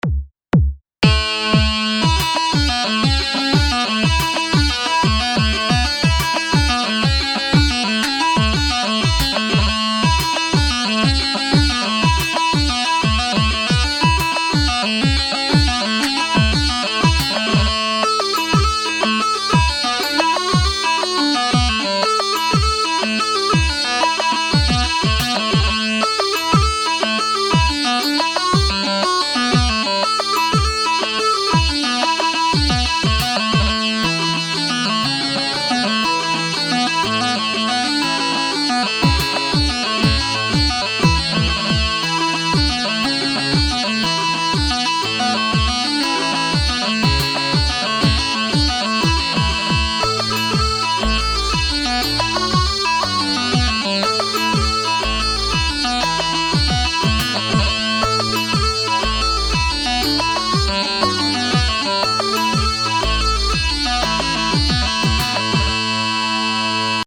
Tune of the Month
Fraher’s Jig < Garageband
frahersjiggarageband.mp3